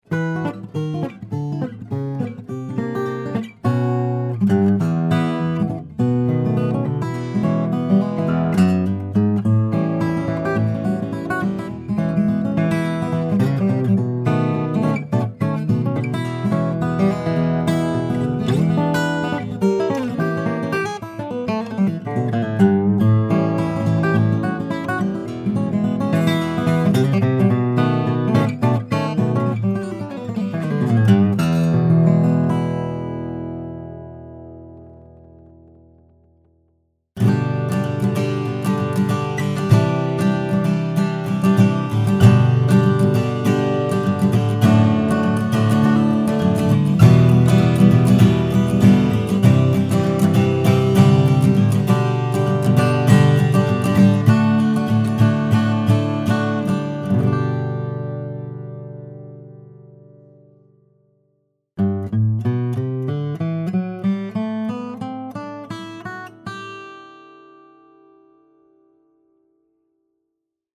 The voice of this guitar is dense and throaty with a lot of weight on each string.